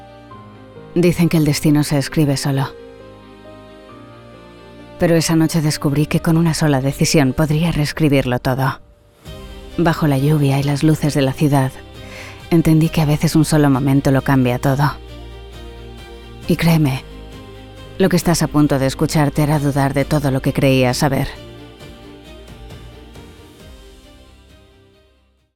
Versatile, Elegant, Sincere, Warm tones. 30-40.
MOVIE TRAILER
Promo, Cool, Dramatic, Confident